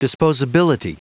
Prononciation du mot disposability en anglais (fichier audio)
Prononciation du mot : disposability